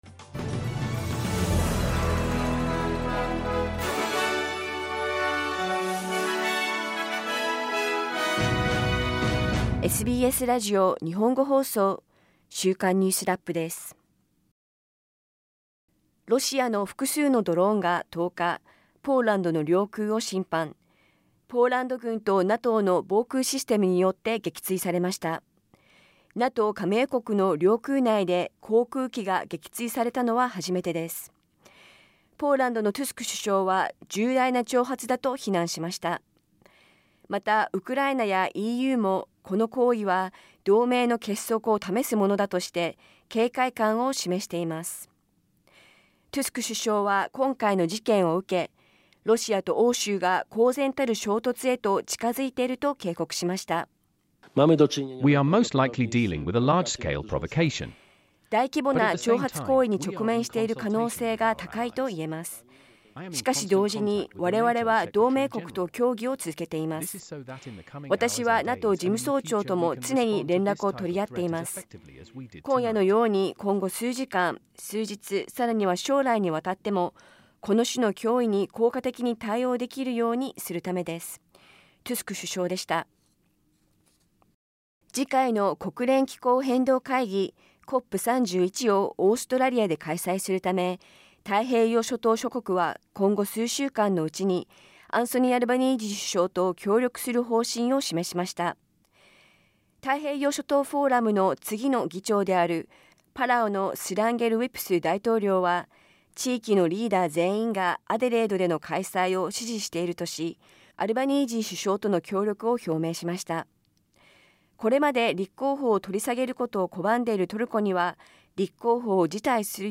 1週間を振り返るニュースラップです。